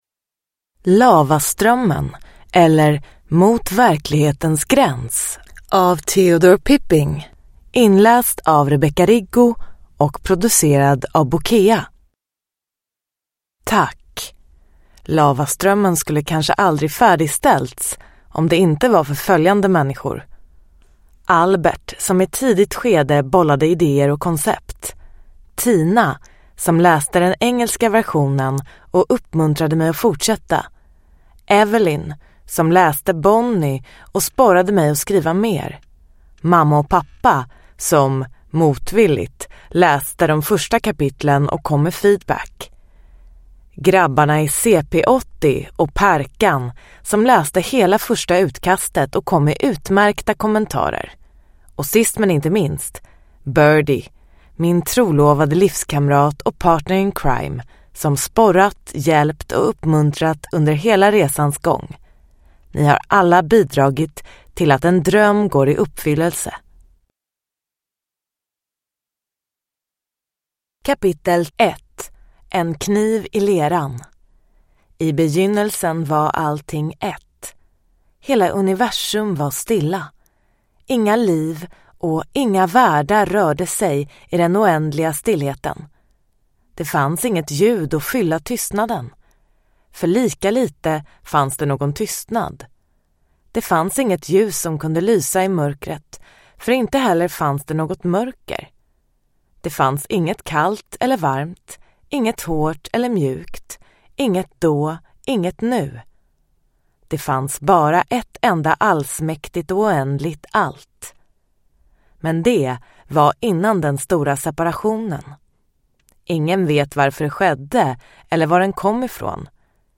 Lavaströmmen : eller mot verklighetens gräns – Ljudbok